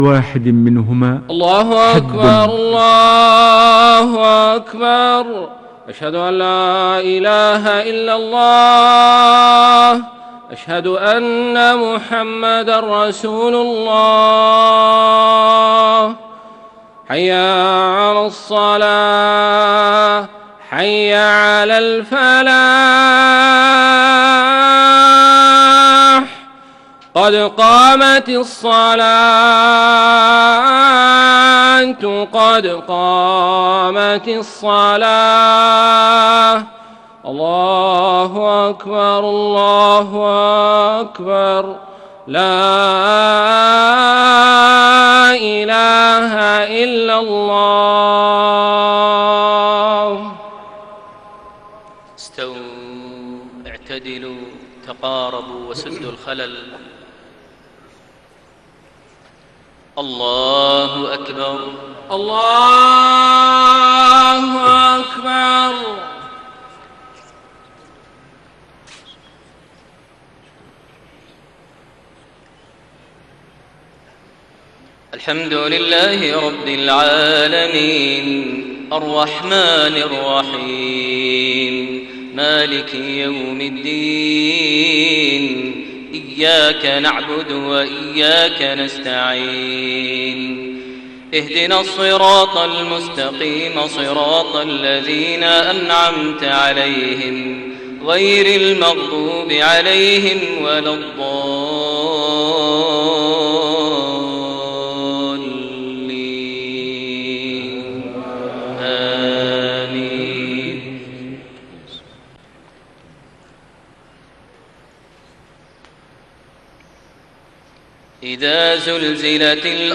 صلاة المغرب 9 ذو القعدة 1432هـ سورتي الزلزلة و القارعة > 1432 هـ > الفروض - تلاوات ماهر المعيقلي